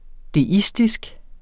Udtale [ deˈisdisg ]